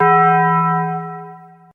disengage.wav